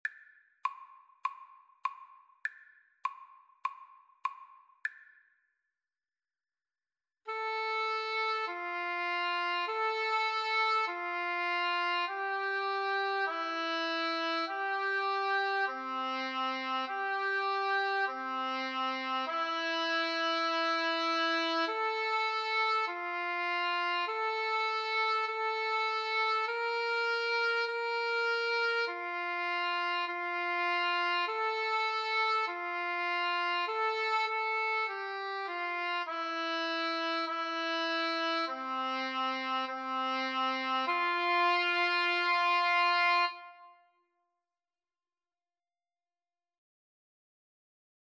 4/4 (View more 4/4 Music)
Espressivo
Oboe Duet  (View more Easy Oboe Duet Music)
Classical (View more Classical Oboe Duet Music)